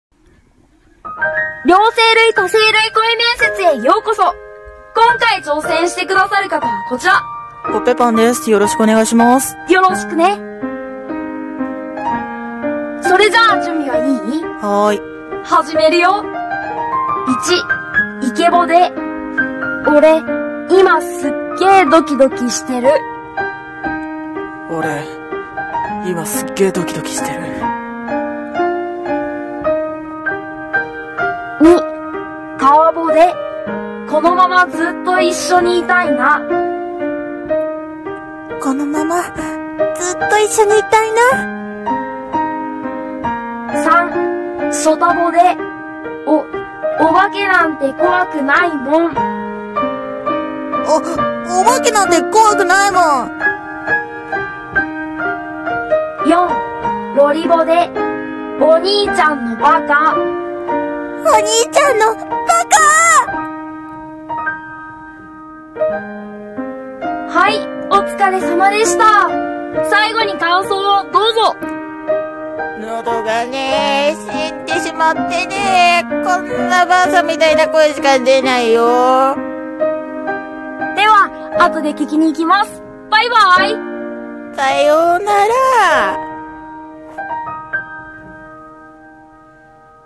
両声類・多声類声面接